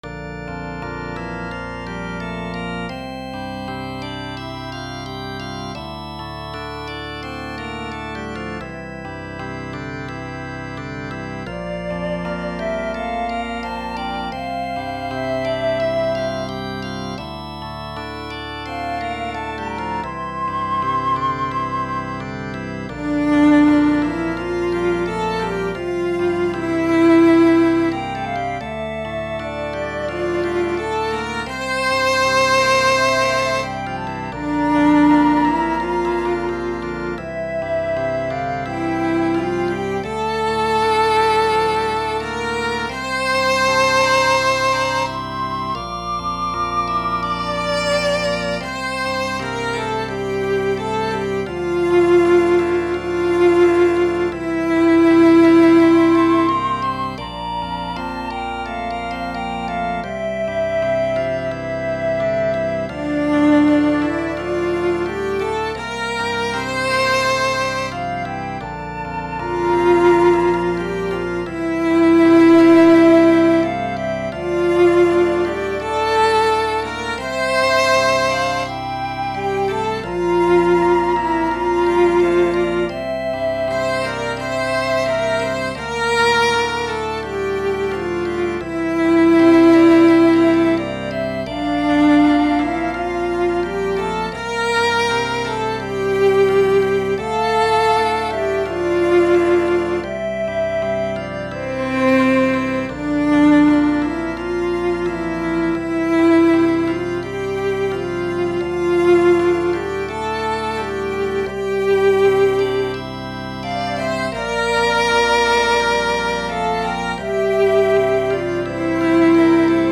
Flute Solo, Organ/Organ Accompaniment, Vocal Solo
Voicing/Instrumentation: Flute Solo , Organ/Organ Accompaniment We also have other 1 arrangement of " A Prayer ".
Vocal Solo Medium Voice/Low Voice